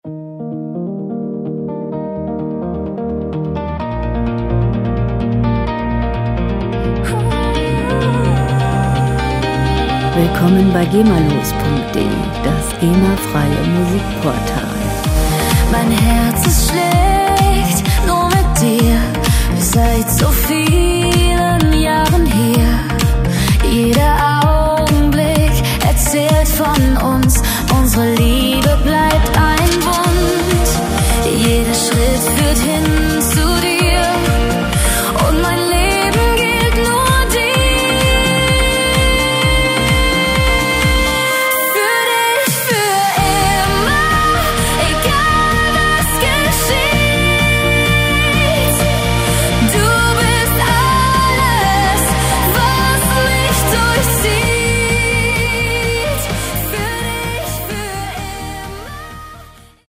Musikstil: EDM Deutschpop
Tempo: 127 bpm
Tonart: Es-Dur
Charakter: romantisch, leidenschaftlich